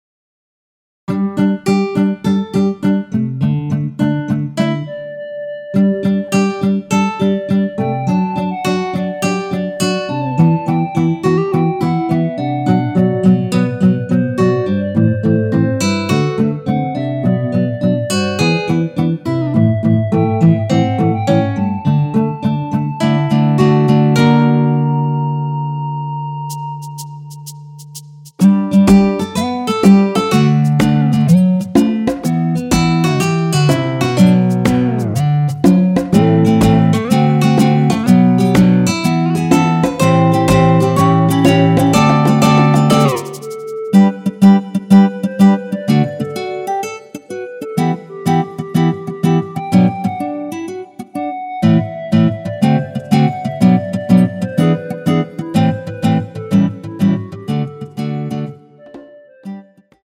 전주 없이 시작 하는곡이라 노래 하시기 편하게 전주 2마디 많들어 놓았습니다.(미리듣기 확인)
원키에서(+3)올린 멜로디 포함된 MR입니다.
앞부분30초, 뒷부분30초씩 편집해서 올려 드리고 있습니다.
중간에 음이 끈어지고 다시 나오는 이유는